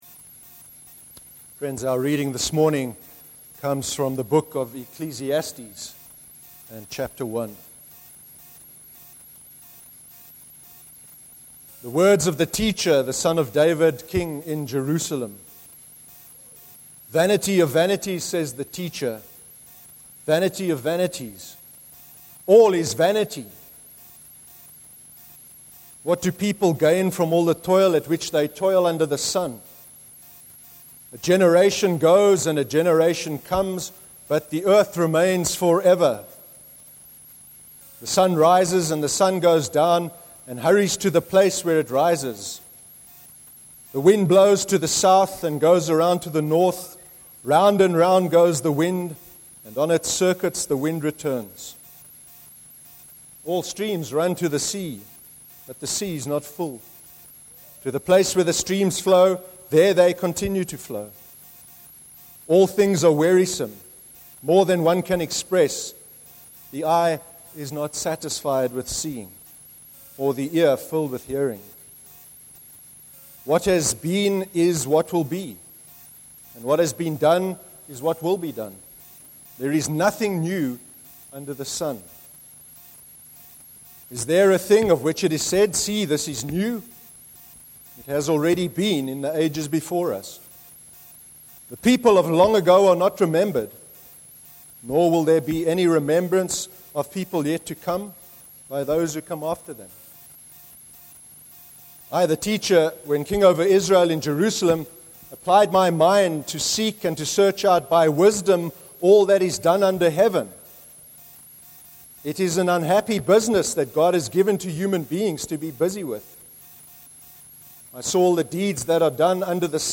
11/08/13 sermon – Despair – why certain things happen the way they do, and how we try to make sense of them (Ecclesiastes 1)